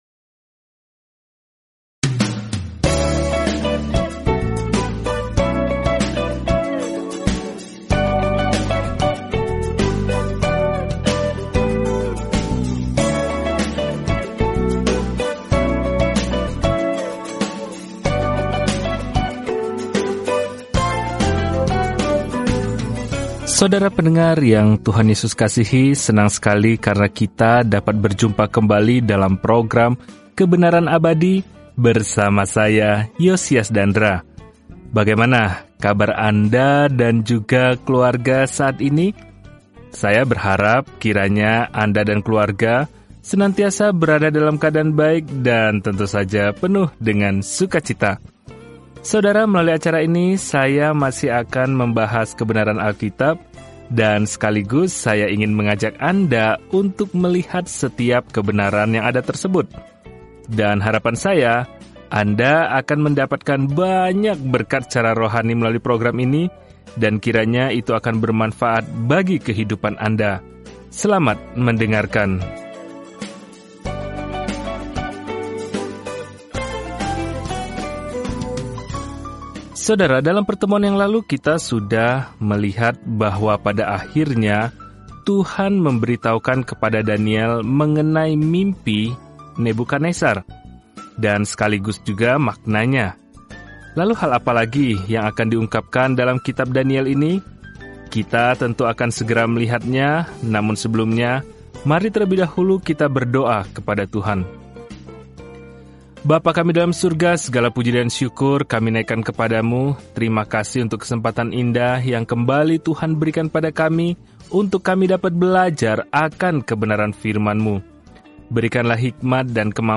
Firman Tuhan, Alkitab Daniel 2:40-44 Hari 5 Mulai Rencana ini Hari 7 Tentang Rencana ini Kitab Daniel merupakan biografi seorang pria yang percaya kepada Tuhan dan visi kenabian tentang siapa yang pada akhirnya akan memerintah dunia. Telusuri Daniel setiap hari sambil mendengarkan studi audio dan membaca ayat-ayat tertentu dari firman Tuhan.